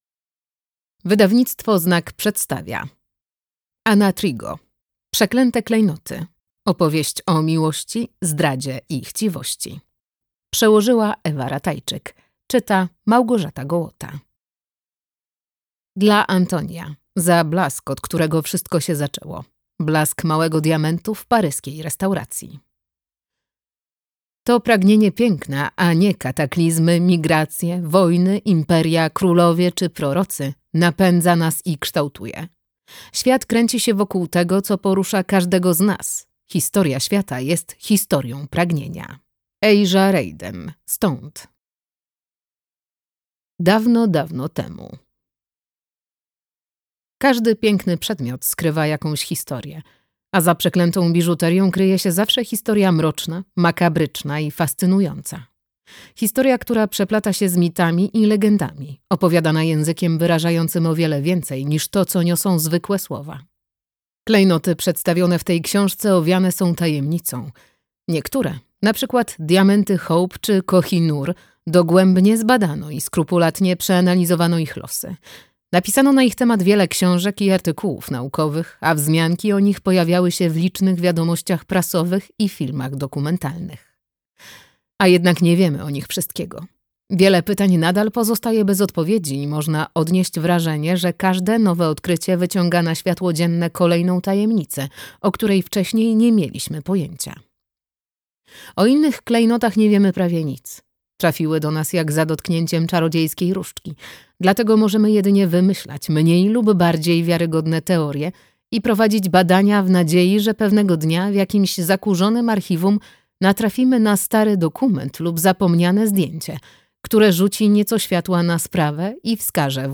Przeklęte klejnoty. Opowieść o miłości, zdradzie i chciwości - Ana Trigo - audiobook + książka